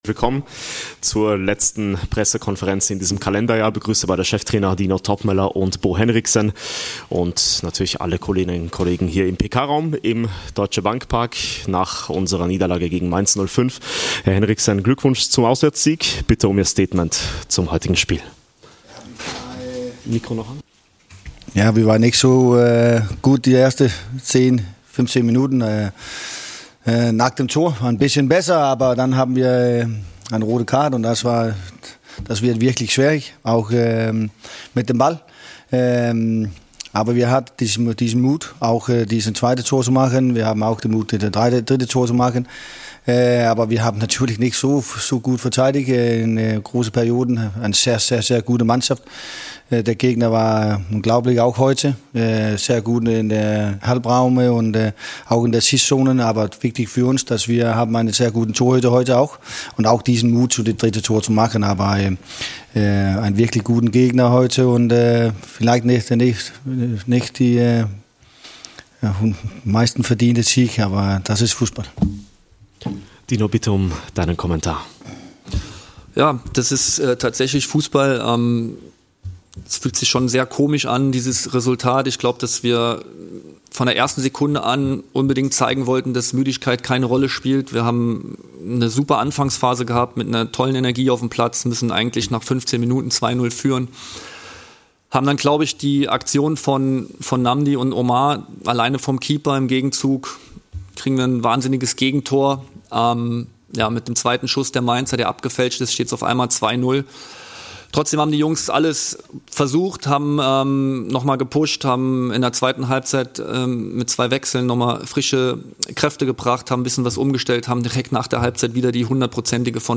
Die Pressekonferenz mit Dino Toppmöller und Bo Henriksen nach dem Spiel zwischen Eintracht Frankfurt und dem 1. FSV Mainz 05.